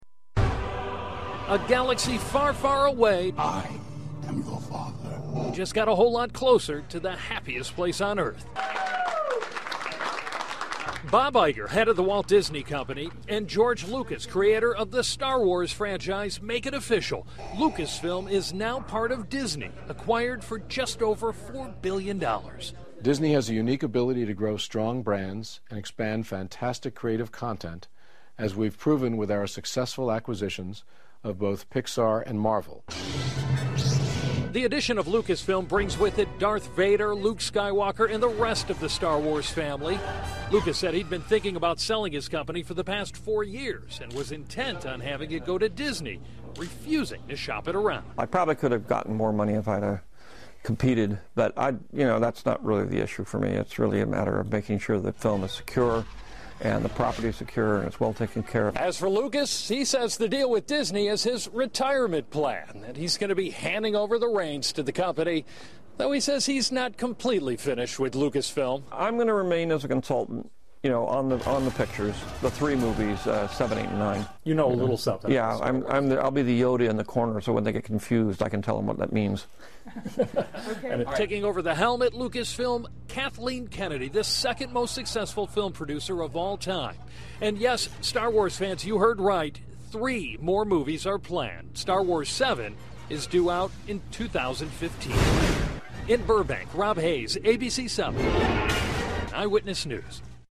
访谈录 2012-11-03&11-05 星球大战之父乔治·卢卡斯 听力文件下载—在线英语听力室